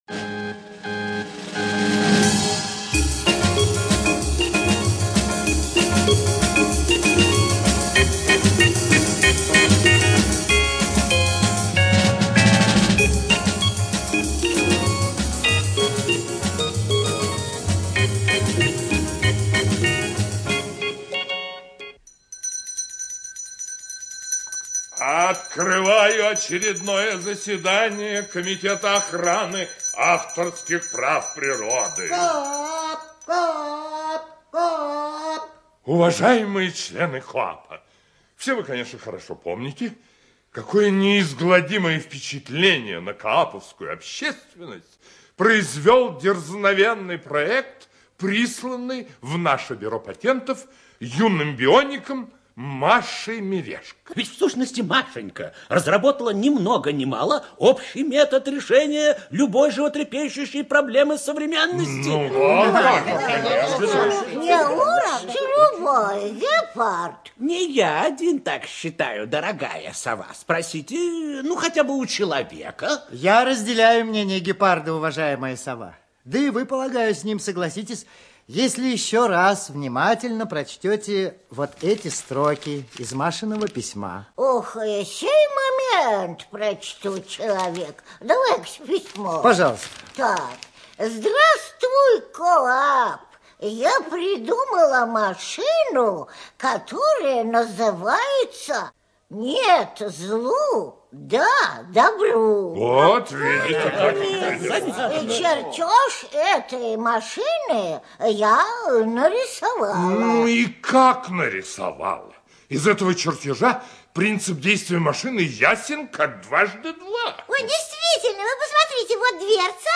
ЖанрДетская литература, Научно-популярная литература, Радиопрограммы